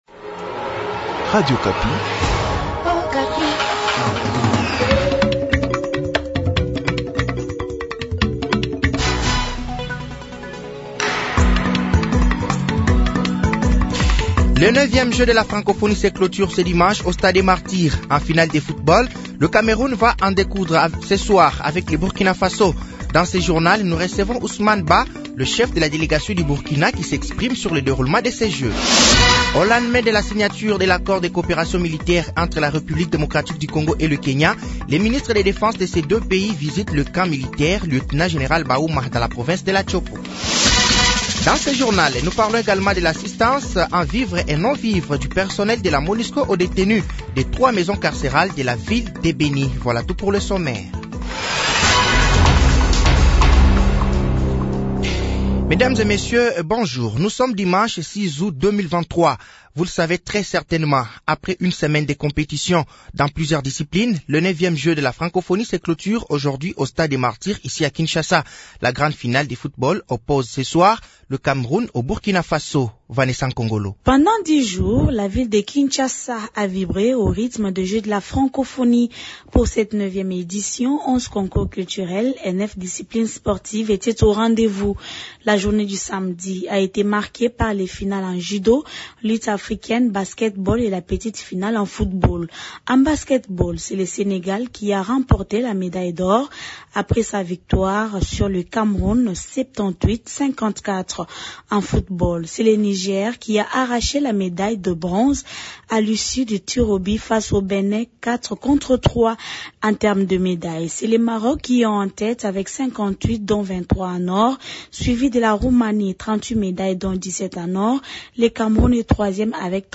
Journal français de 7h de ce dimanche 06 août 2023